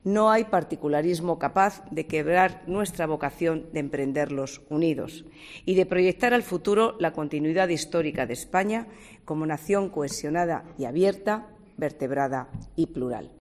De Cospedal ha transmitido ese mensaje durante su discurso en la ceremonia de la Pascua Militar, presidida por Felipe VI en el Palacio Real en compañía de su padre, don Juan Carlos, como homenaje por su 80 cumpleaños.